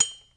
ding hit metal ring ting tone sound effect free sound royalty free Sound Effects